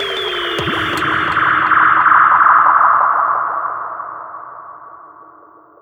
FILL FX 01-R.wav